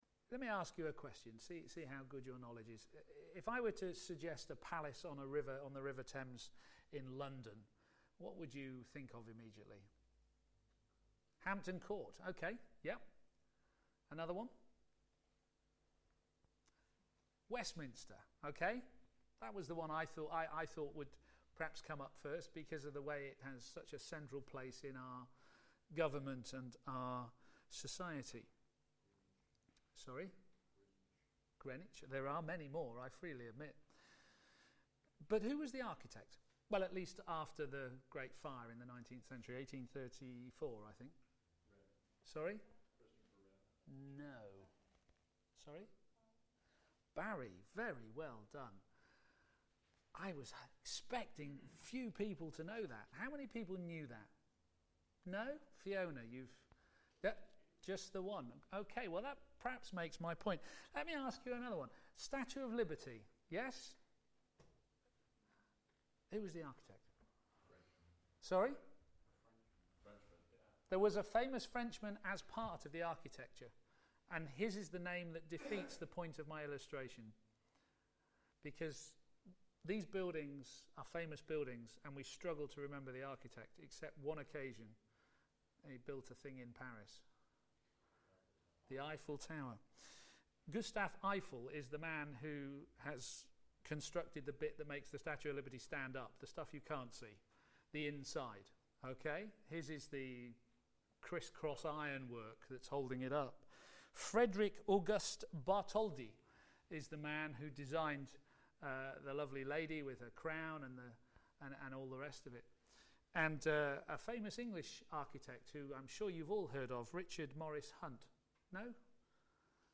p.m. Service
Builders for God on the Only Foundation: Our Lord Jesus Christ Sermon